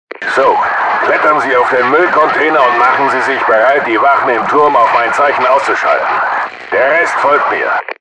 picture x Helikopterpilot: